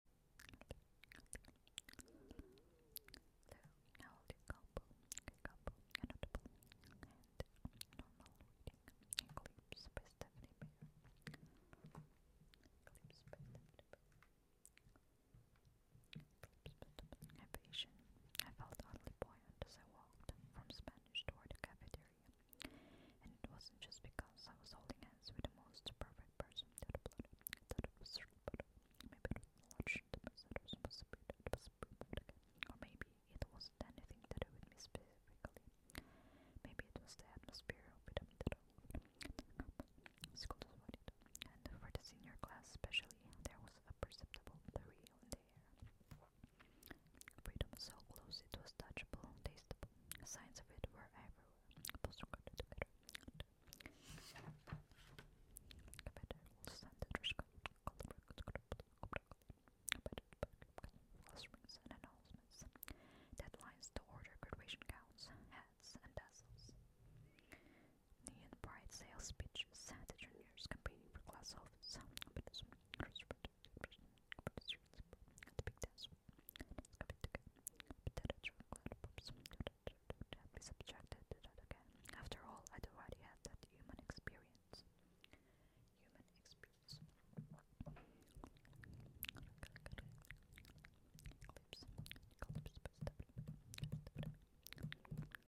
ASMR “Combo” Inaudible | Reading